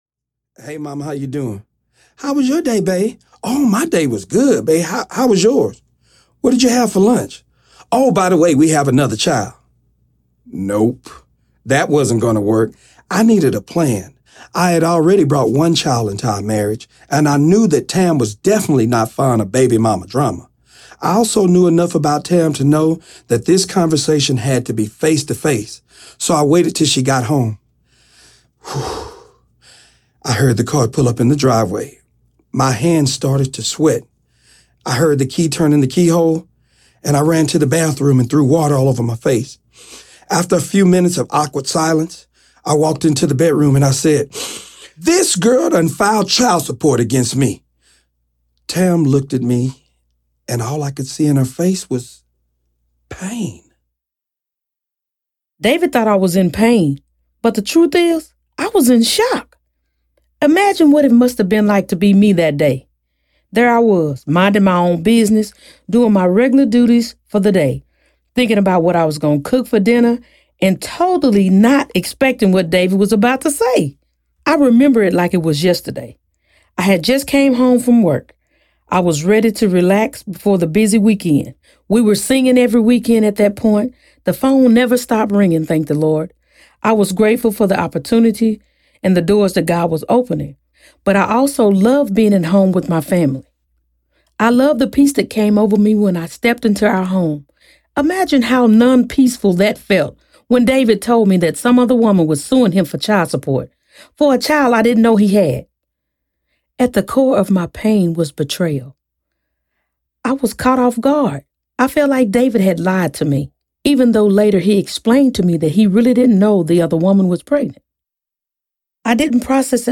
Us Against the World Audiobook